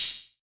Tm8_HatxPerc29.wav